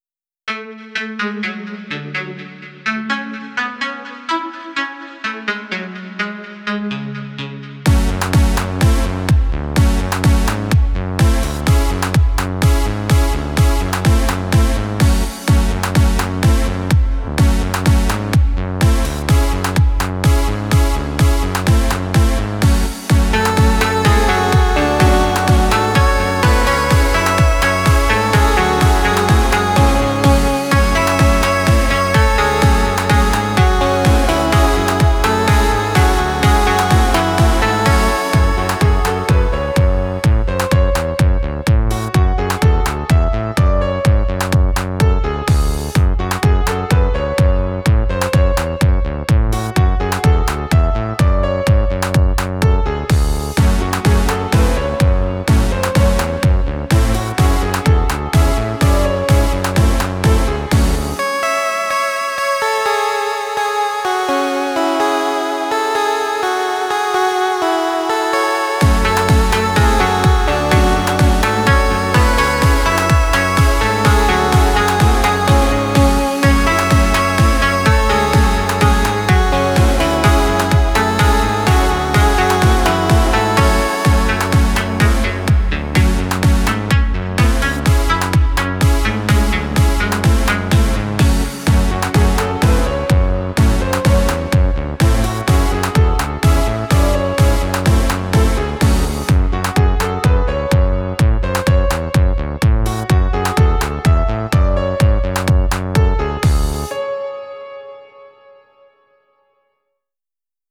BEST ELECTRO G-Q (39)